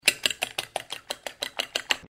搅拌.mp3